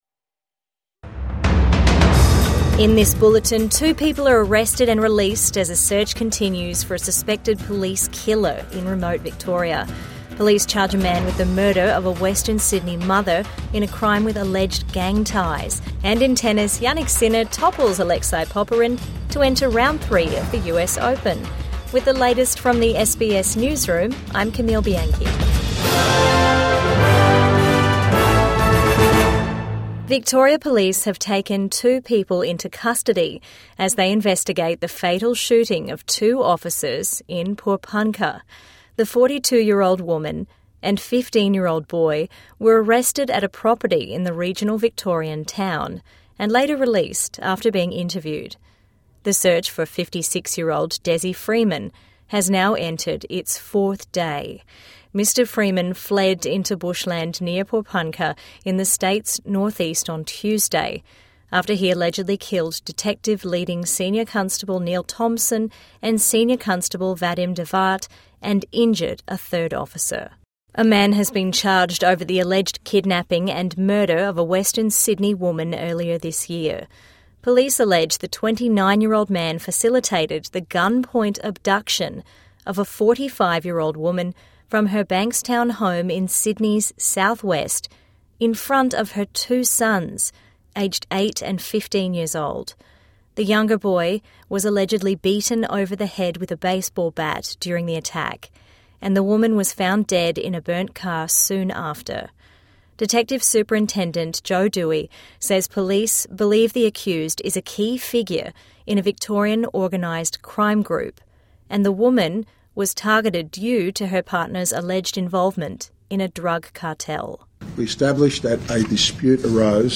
Two arrested, released in search for fugitive alleged killer | Midday News Bulletin 29 August 2025